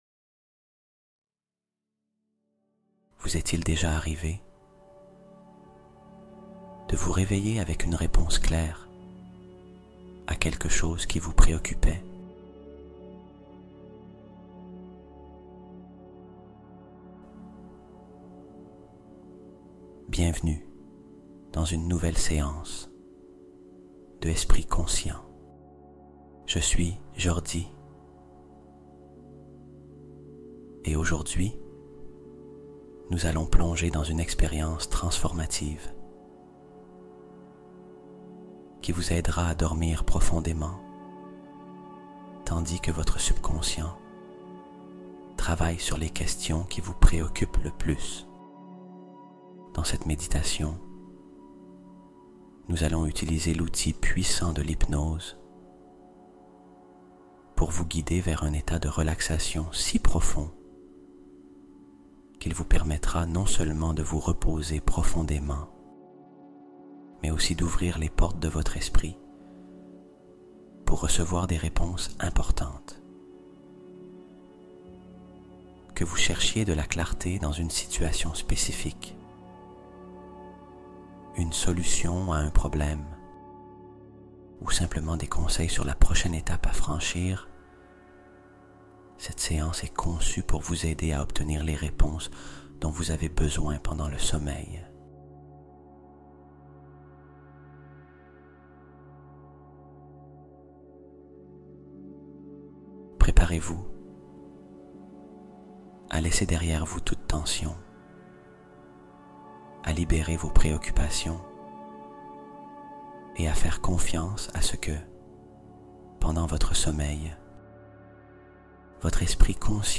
Méditation guidée pour dormir – la clé dorée du repos profond